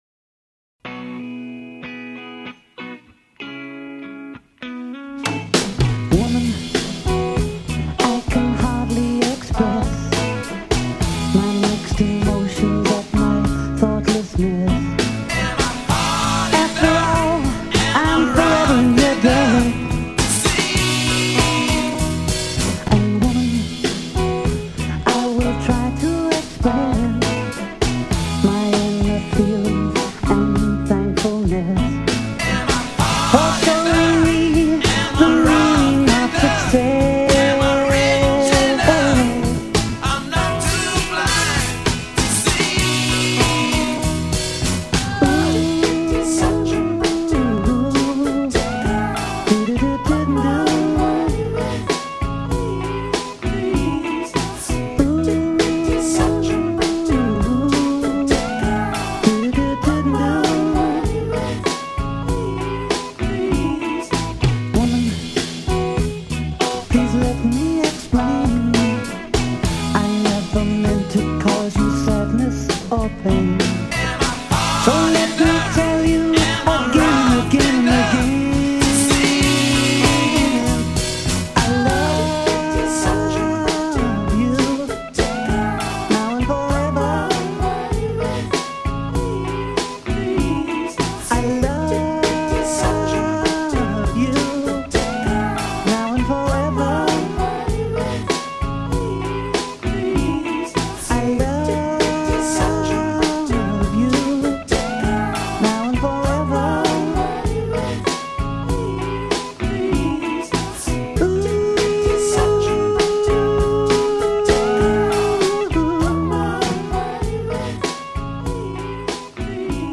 I have a new mash-up for you folks to download